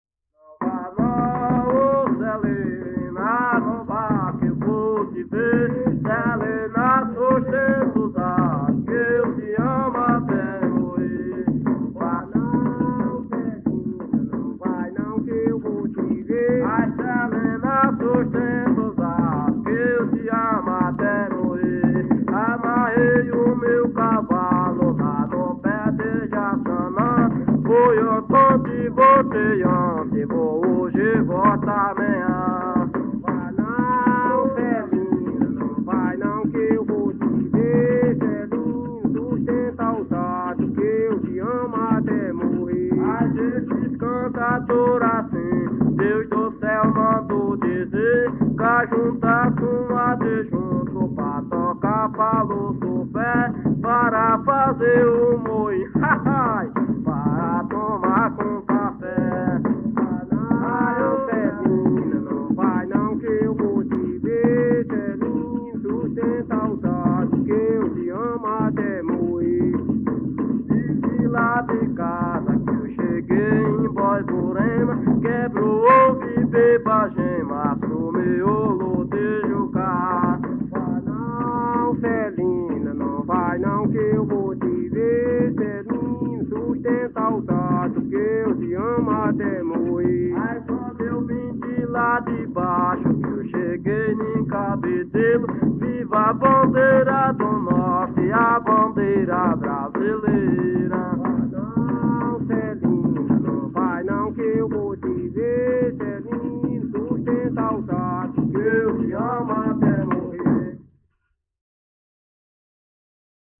Coco- “”Celina”” - Acervos - Centro Cultural São Paulo